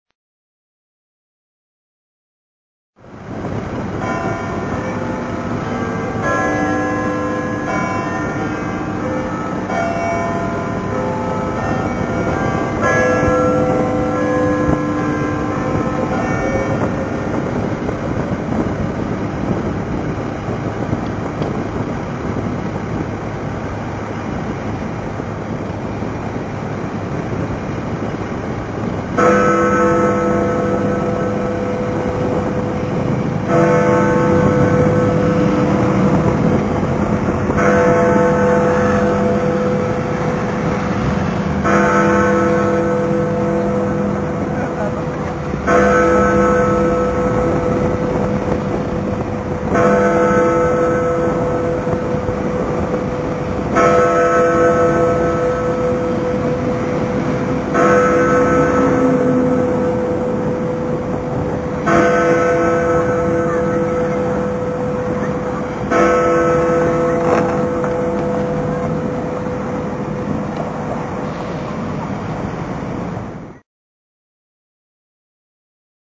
ウェストミンスターの（ビッグベンの）鐘
10westminster.mp3